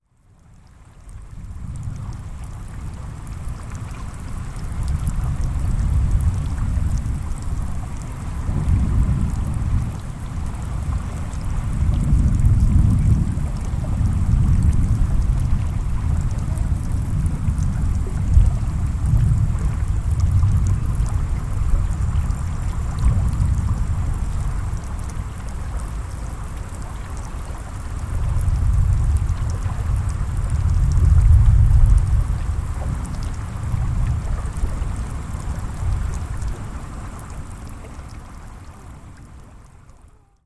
• rolling thunderstorm and light rain near sea.wav
rolling_thunderstorm_and_light_rain_near_sea_1MO.wav